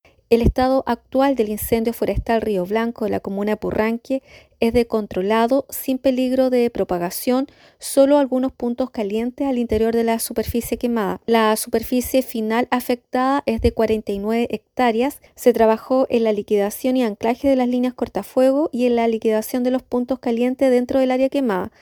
Así lo confirmó la delegada presidencial provincial de Osorno, Claudia Pailalef.